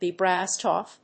アクセントbe brássed óff